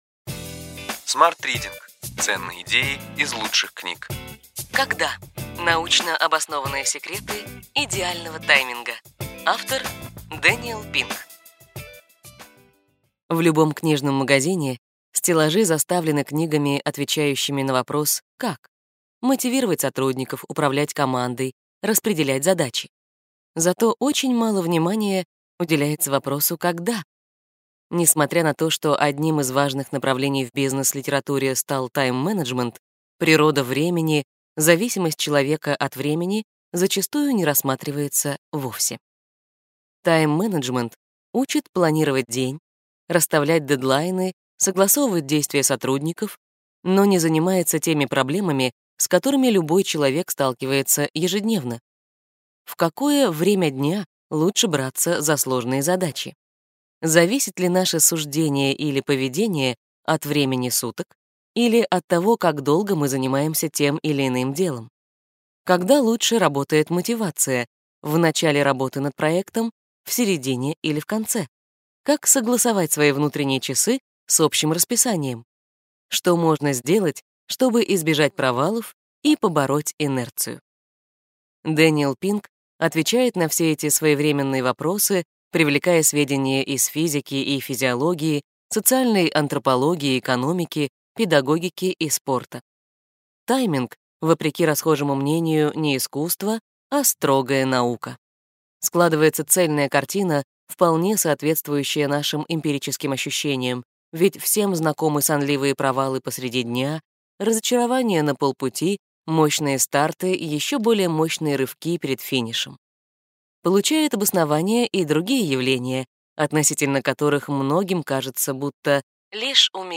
Аудиокнига Ключевые идеи книги: Когда: научно обоснованные секреты идеального тайминга. Дэниел Пинк | Библиотека аудиокниг